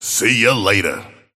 Abrams voice line - See ya later.